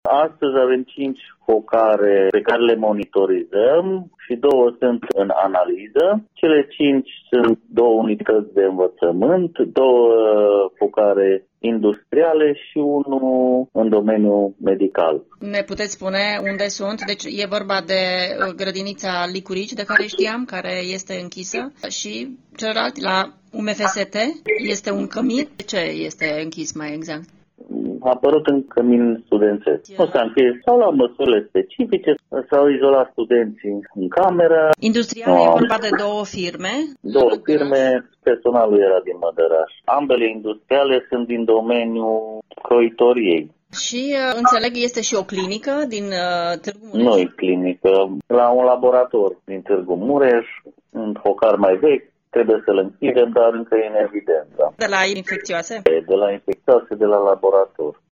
Mai multe aflăm de la directorul Direcției de Sănătate Publică Mureș, dr. Iuliu Moldovan: